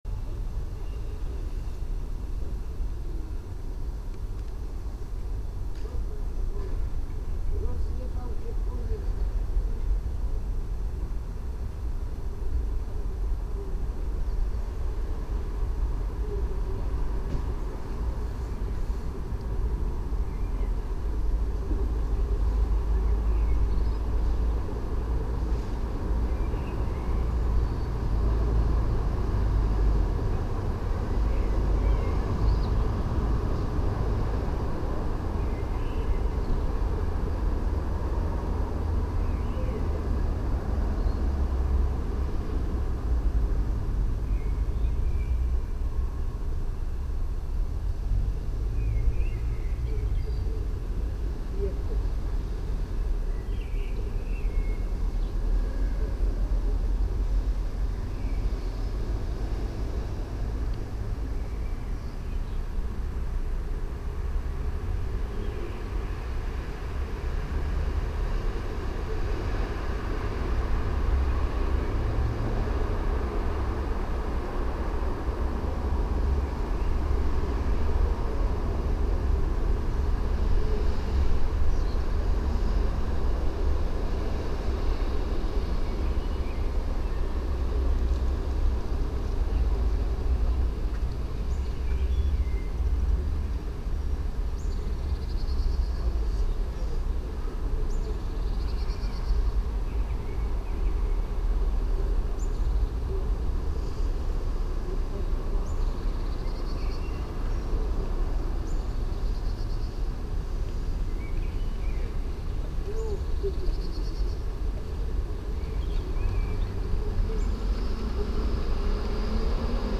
Einsiedlerpark, Vienna - rec 2016
8 Minutes of the 24 h Einsiedlerpark.mp3